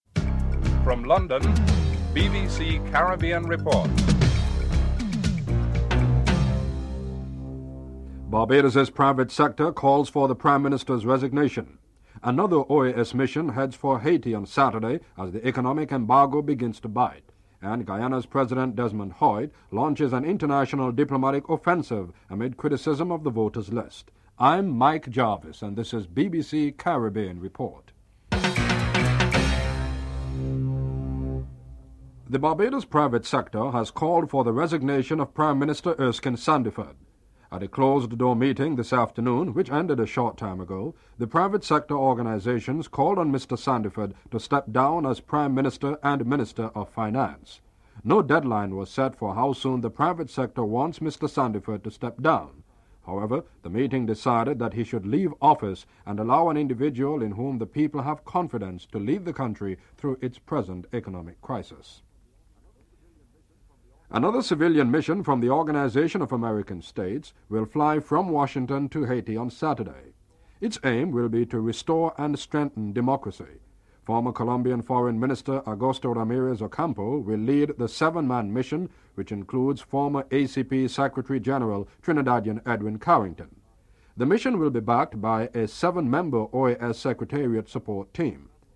Distorted aduio during the interview with Cheddie Jagan and the reports ends abruptly.